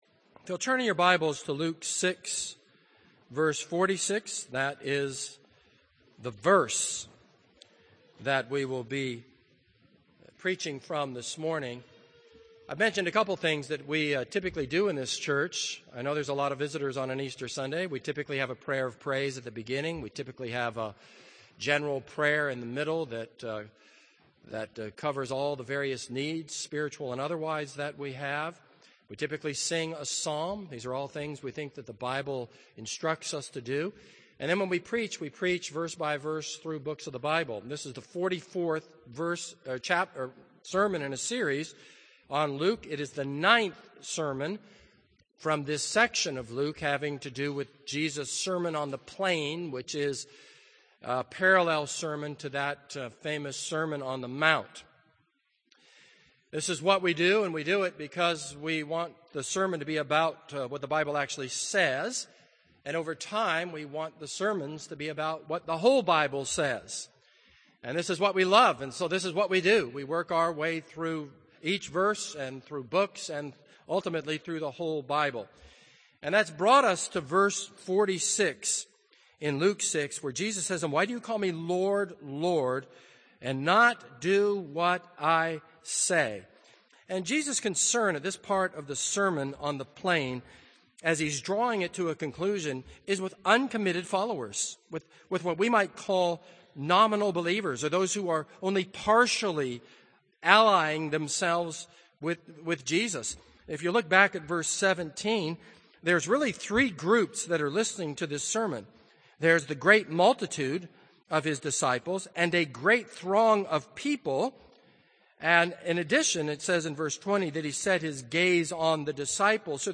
This is a sermon on Luke 6:46.